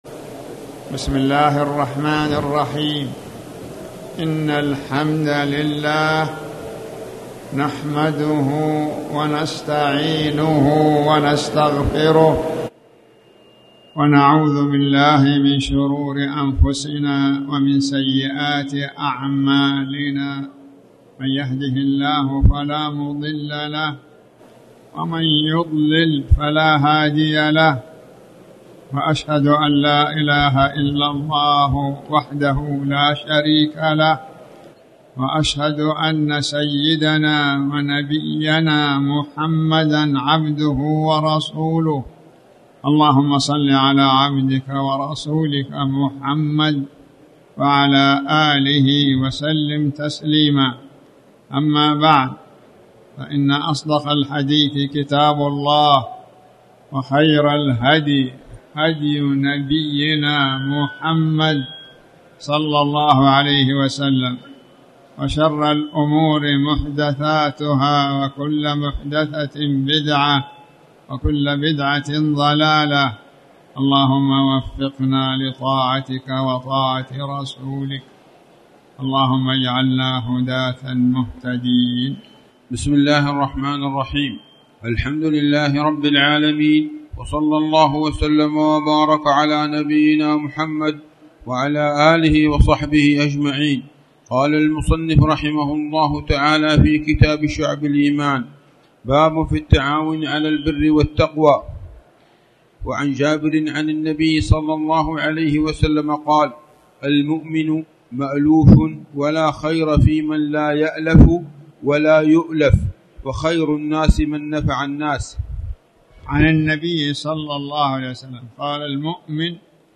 تاريخ النشر ١١ محرم ١٤٣٩ هـ المكان: المسجد الحرام الشيخ